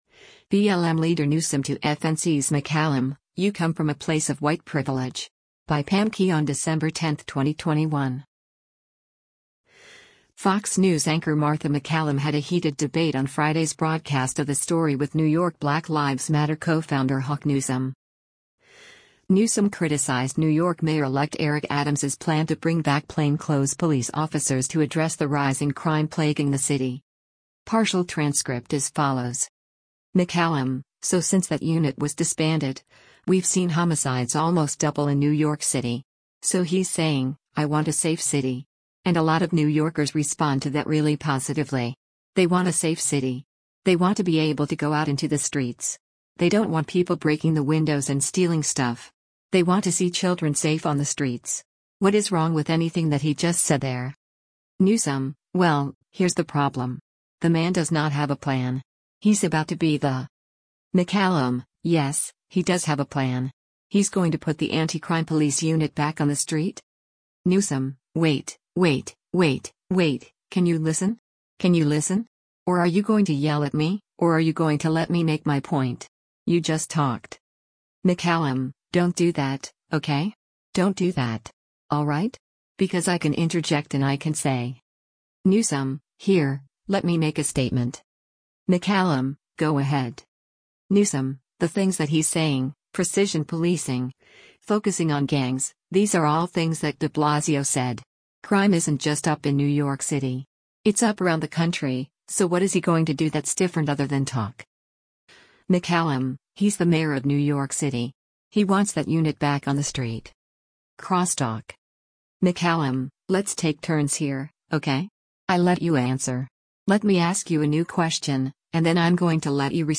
Fox News anchor Martha MacCallum had a heated debate on Friday’s broadcast of “The Story” with New York Black Lives Matter co-founder Hawk Newsome.